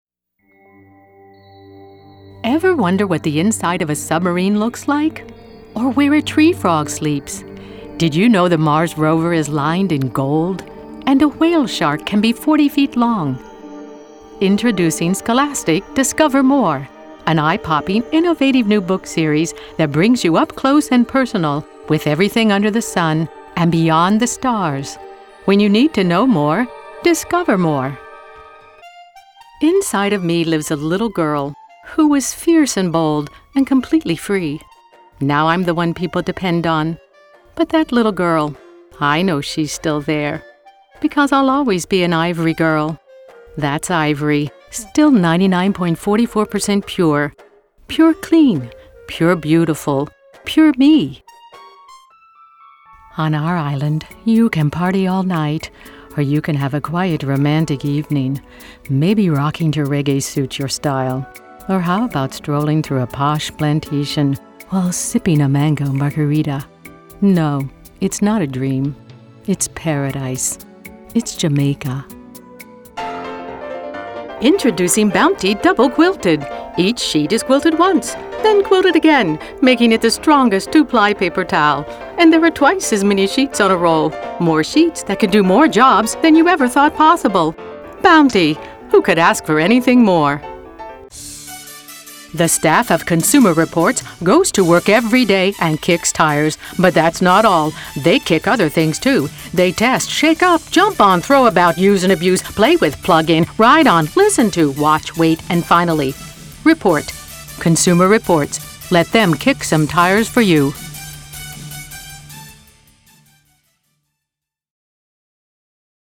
Voice Over Artist